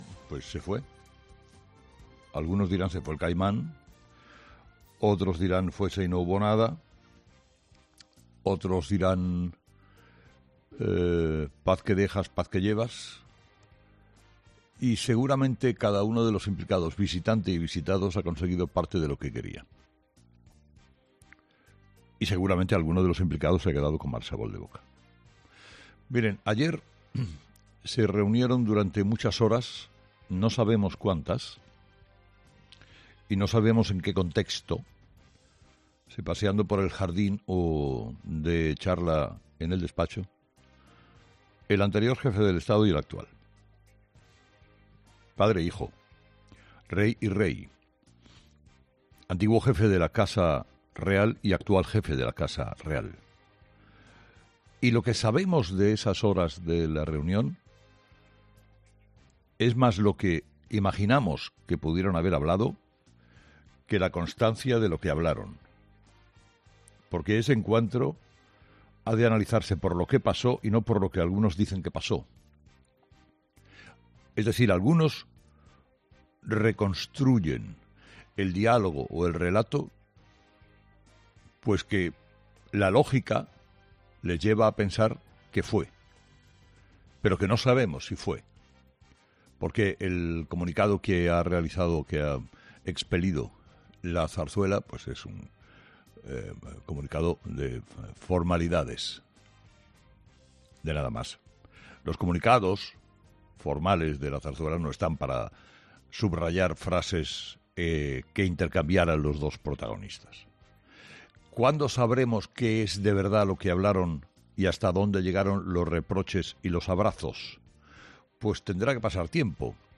Carlos Herrera, director y presentador de 'Herrera en COPE', ha comenzado el programa de este martes analizando las principales claves de la jornada, que pasan, entre otros asuntos, por el regreso del Rey Don Juan Carlos a Abu Dabi tras pasar unos días en España y por la campaña que ha articulado Moncloa tras la visita del monarca a su país.